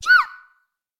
Girl screams (sound effects)
Voice
A scream when a girl sees a scary insect....